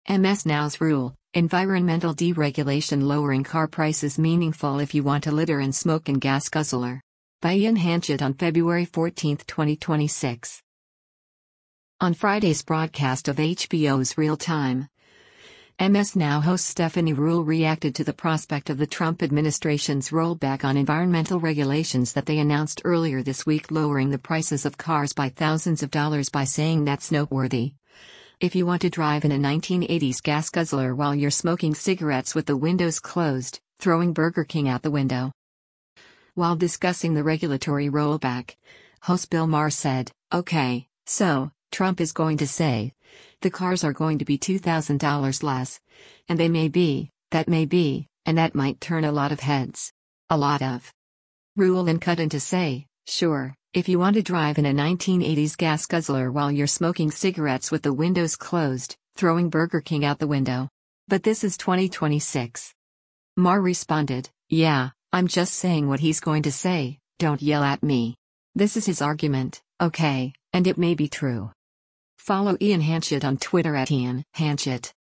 Ruhle then cut in to say, “Sure, if you want to drive in a 1980s gas guzzler while you’re smoking cigarettes with the windows closed, throwing Burger King out the window. But this is 2026.”